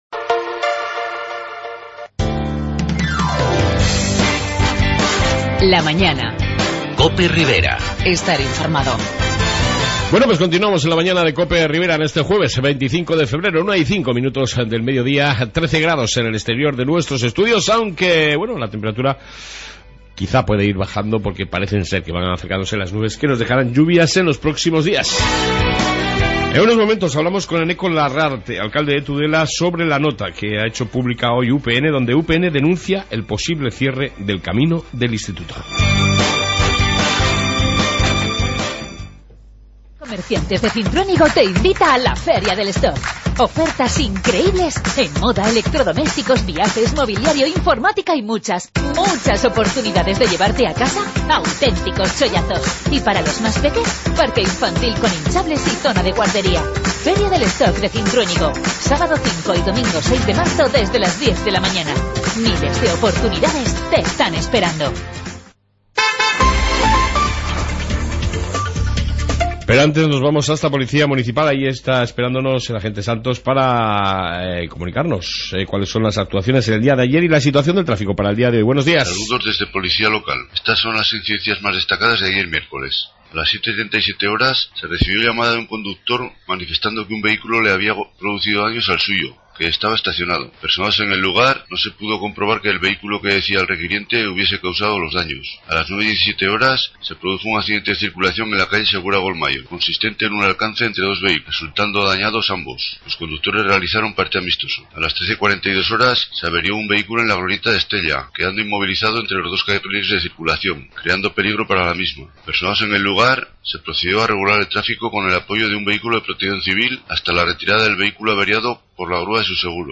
AUDIO: En esta 2ª parte Entrevista con el alcalde Eneko larrarte sobre el camino del instituto (Ante la nota emitida por UPN Tudela) y tiempo de...